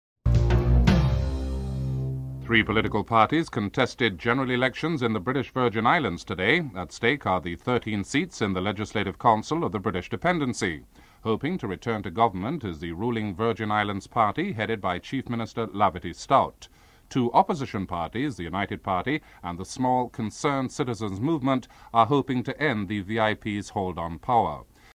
2. Prime Minister Patrick Manning speaks on the currency exchange system and the NAFTA parity bill (03:31-05:07)
5. Dwight Yorke talks about racism in the sport and how it has affected his game (09:30-13:31)